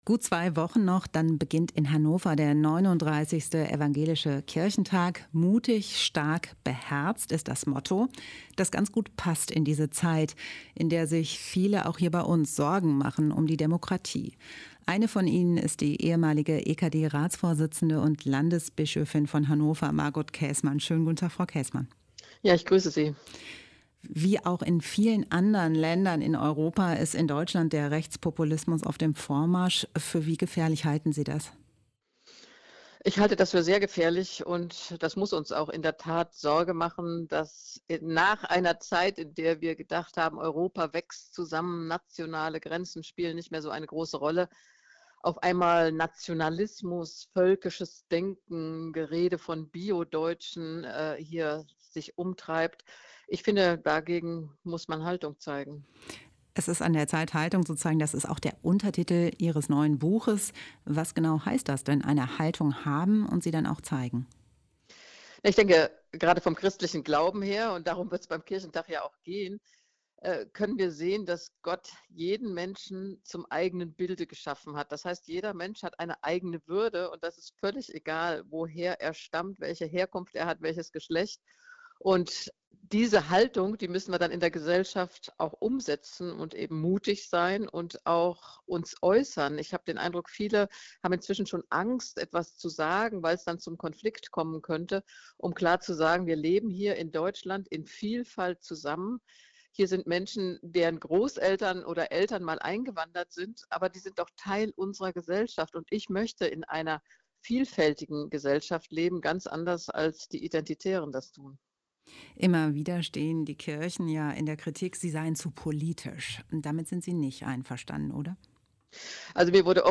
Ein Interview mit Prof. Dr. Dr. Margot Käßmann (ev. Theologin, ehem. EKD-Ratsvorsitzende und Landesbischöfin von Hannover, Autorin)